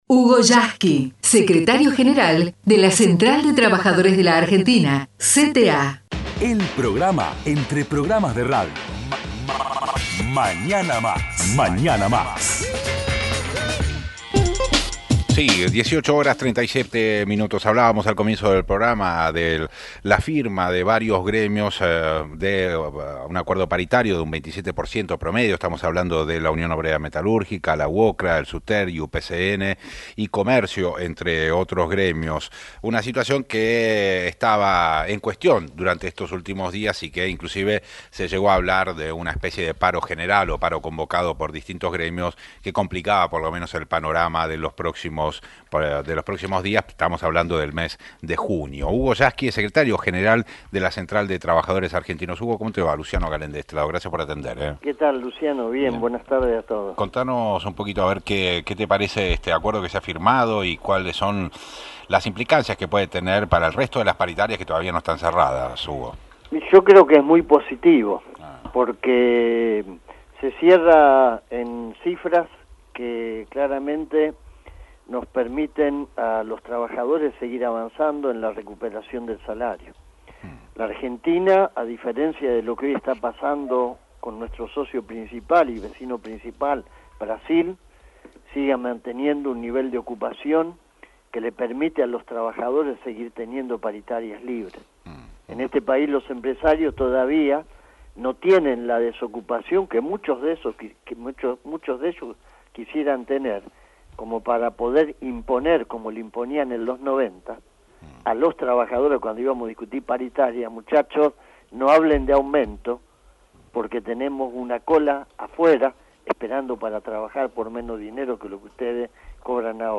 HUGO YASKY entrevistado en RADIO NACIONAL